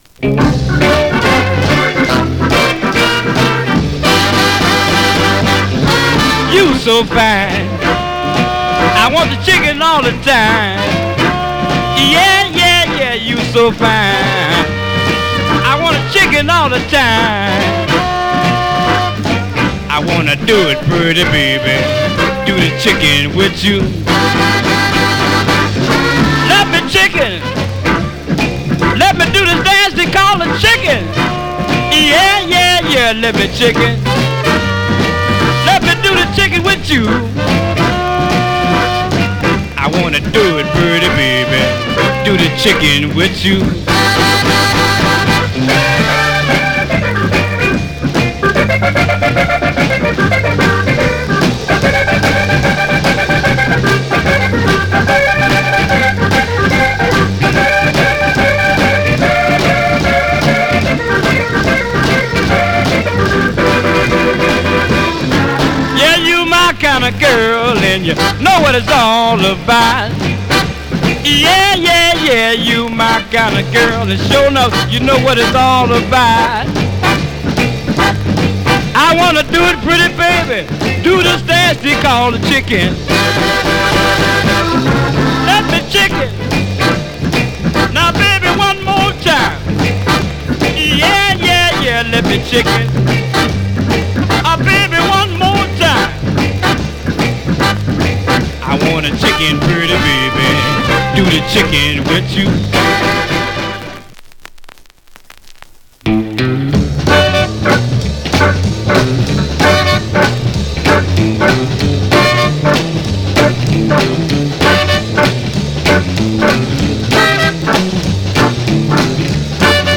オリジナル盤7インチ
B面も同路線なインストでより彼のKillerなギターワークをフューチャー。ハモンドもヒップにうねるフロアシャイカーです。